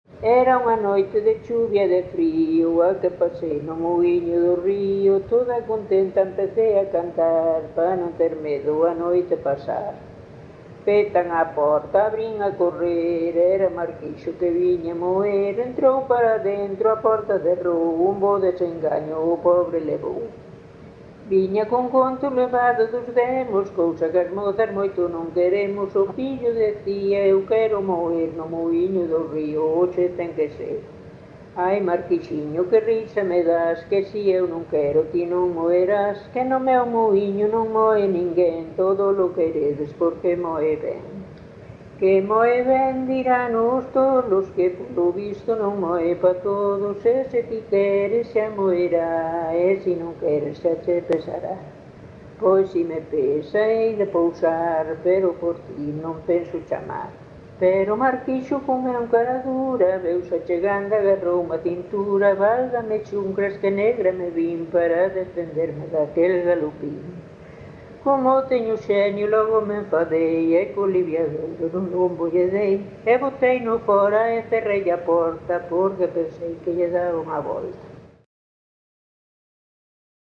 Cantodemuinos.mp3